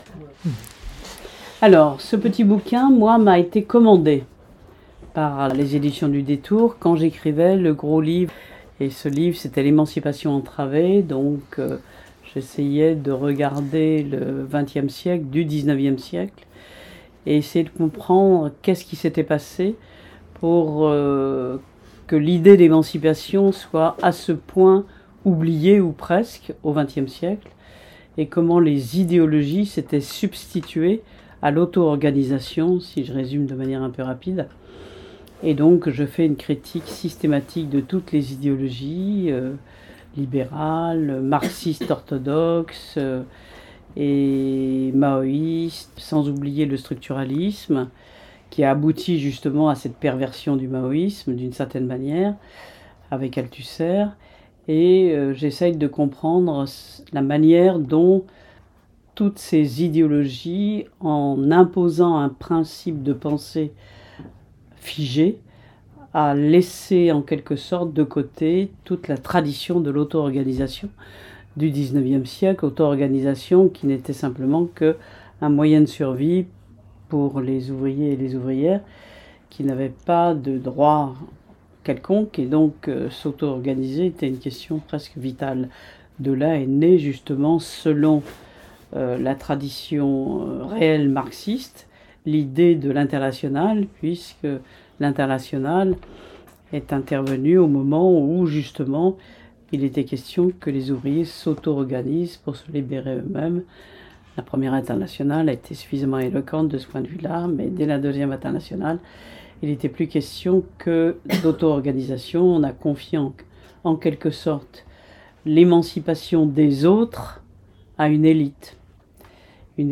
Conférence du 3 mai 2025 : Qu'est devenue l'idée d'émancipation ?